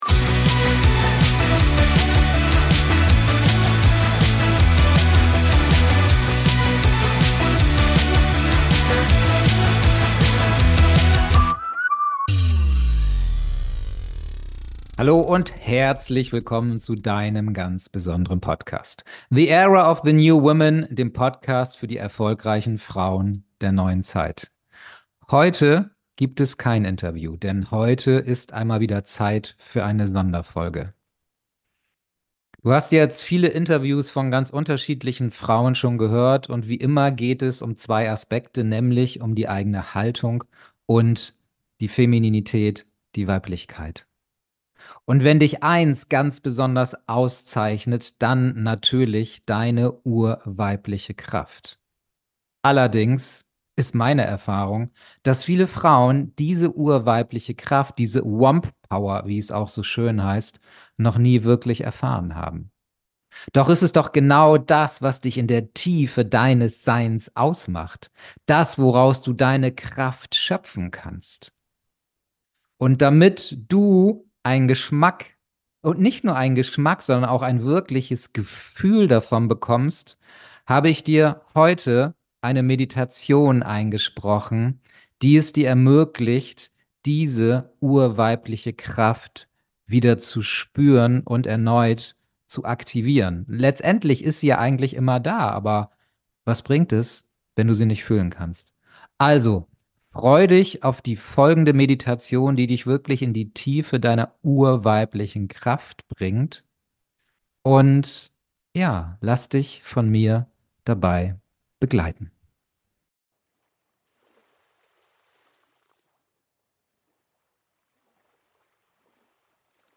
Um diese spüren zu können, führe ich Dich durch eine Meditation, die Dich an diese urweibliche Kraft erinnert und Dich mit ihr verbindet. Fühle die pulsierende Energie und erfahre, wie Du ganz unabhängig vom äußeren Geschehen eins mit Dir wirst.